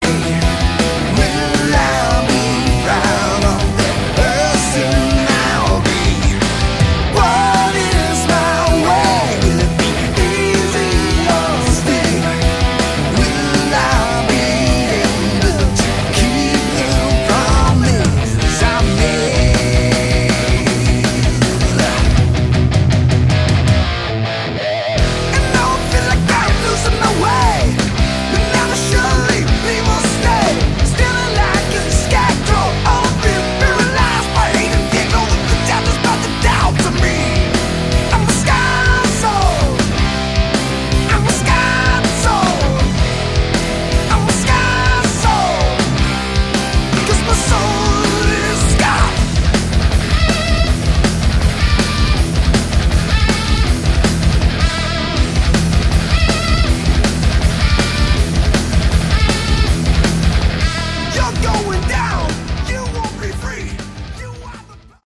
Category: Hard Rock
guitar, vocals
keyboards, Hammond organ